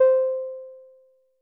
BASS1 C5.wav